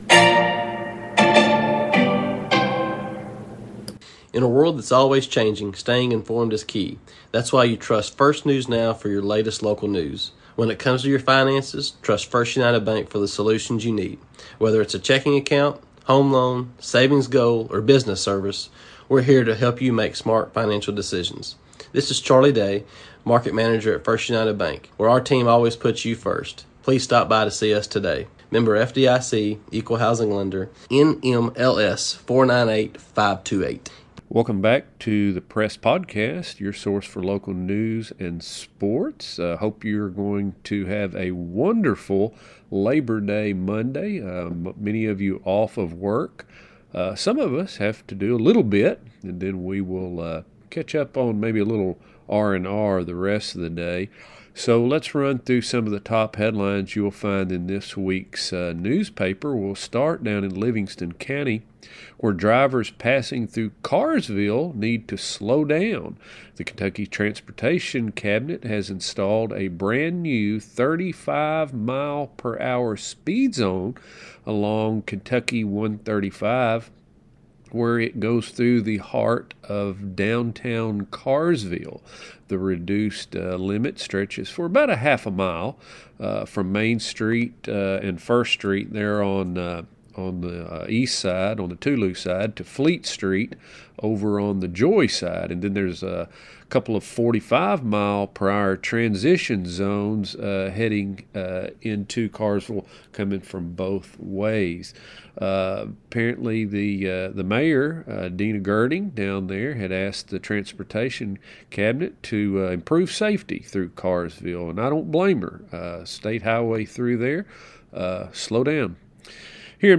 C-Plant | Local NEWScast